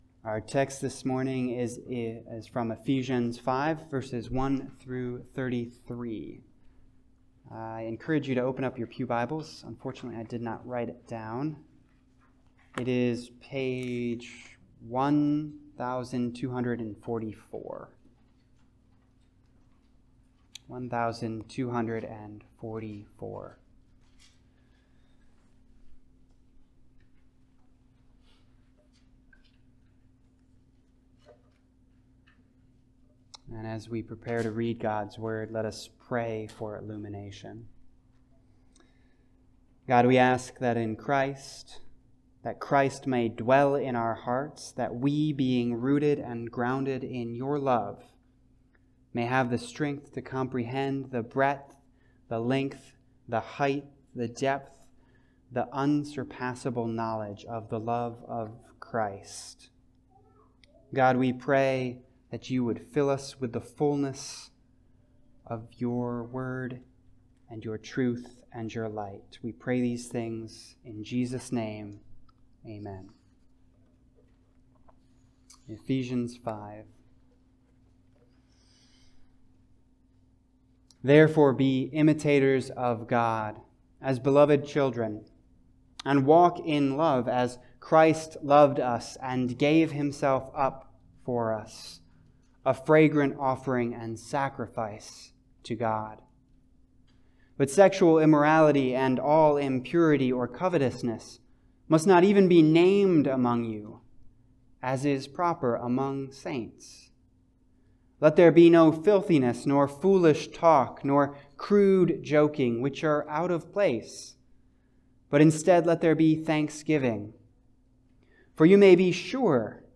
Passage: Ephesians 5:1-33 Service Type: Sunday Service